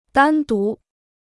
单独 (dān dú): alone; by oneself.